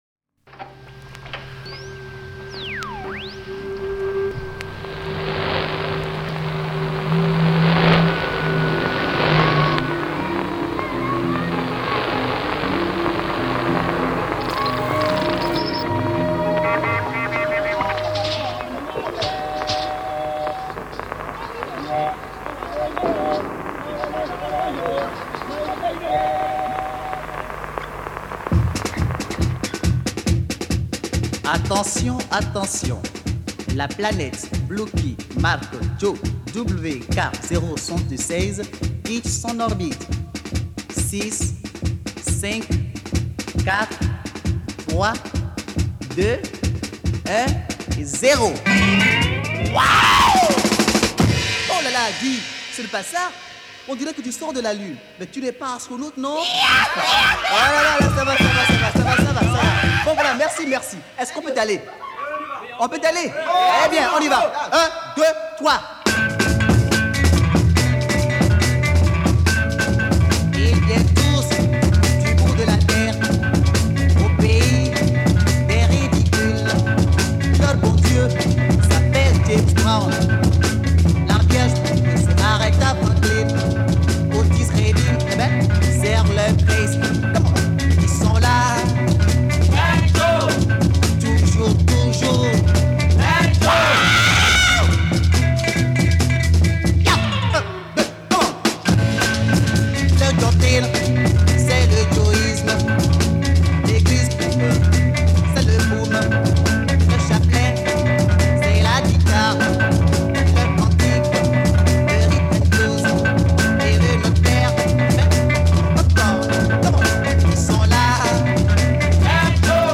Origin of the recordings : Dahomey